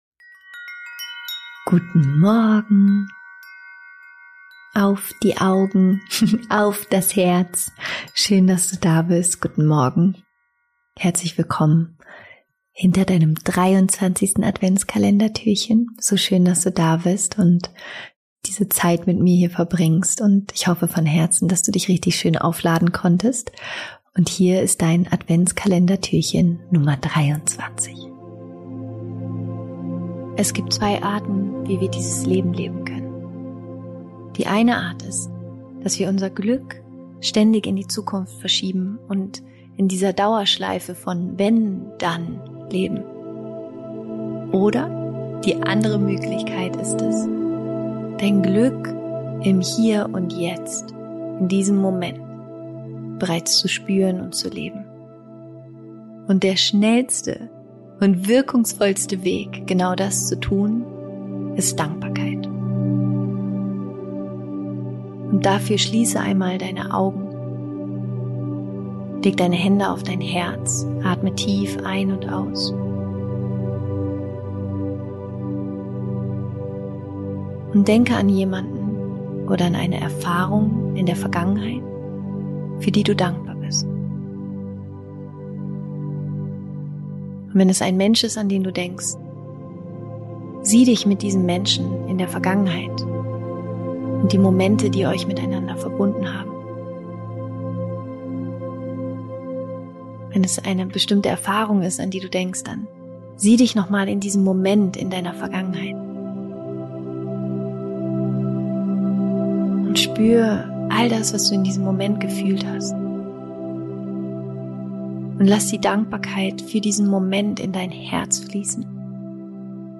Ich hoffe, die heutige Meditation lässt dein Herz vor Freude und Liebe überfließen.